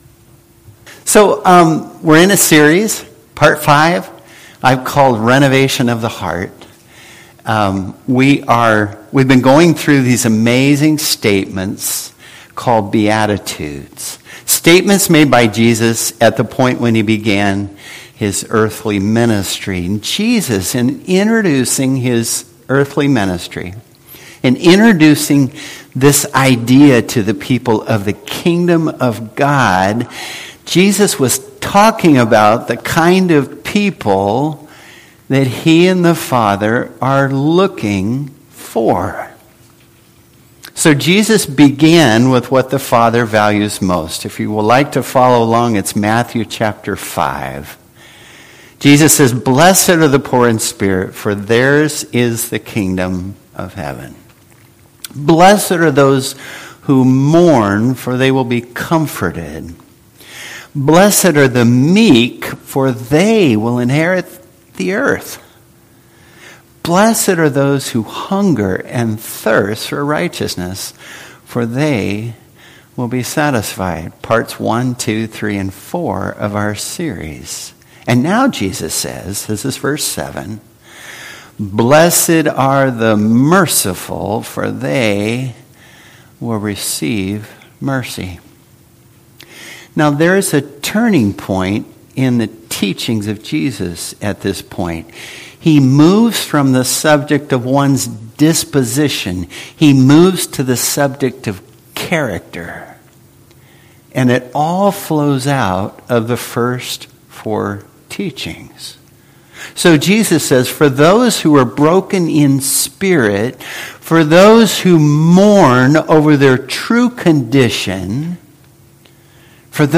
Passage: Hebrews 4:14-16 Service Type: Worship Service